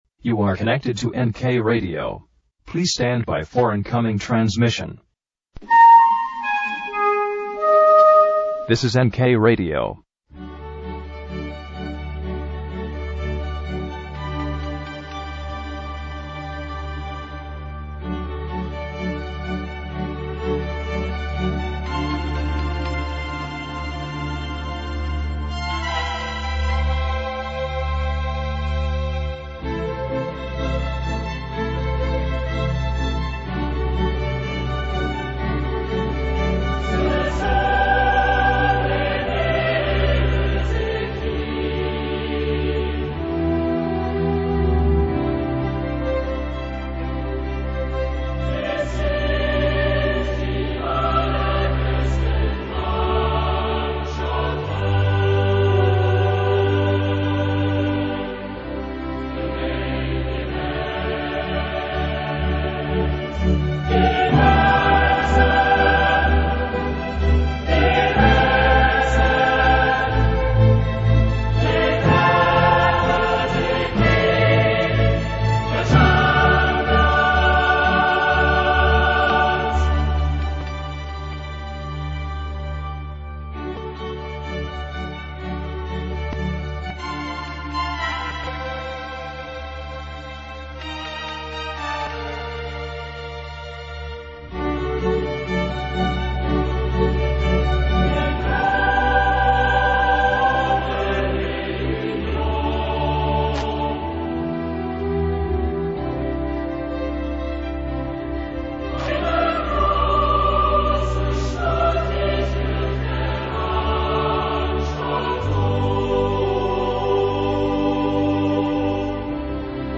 Radiocronaca finale champions league – parte 1 | Radio NK